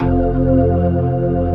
Index of /90_sSampleCDs/AKAI S6000 CD-ROM - Volume 1/VOCAL_ORGAN/POWER_ORGAN
P-ORG2  F1-S.WAV